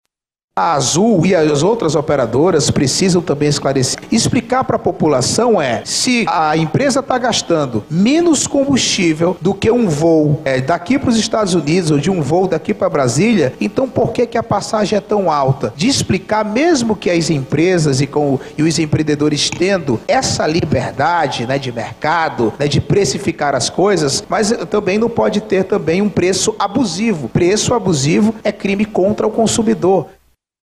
Já o parlamentar Mário César Filho, presidente da Comissão de Defesa do Consumidor da Aleam, relata que trata-se de um vôo doméstico e não internacional.
deputado-Mario-Cesar-Filho.mp3